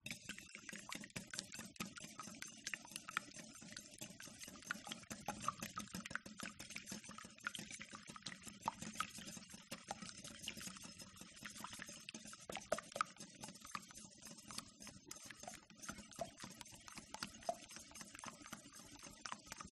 На этой странице собраны натуральные звуки приготовления яичницы: от разбивания скорлупы до аппетитного шипения на сковороде.